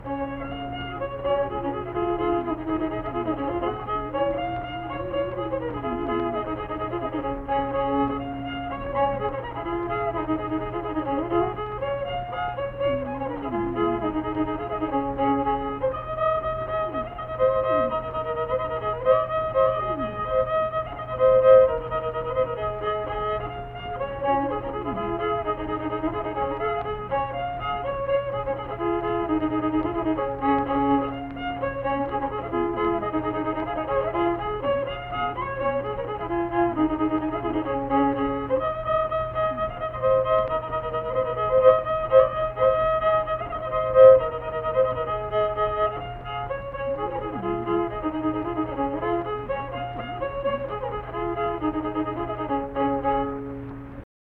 Unaccompanied fiddle music performance
Instrumental Music
Fiddle
Marion County (W. Va.), Mannington (W. Va.)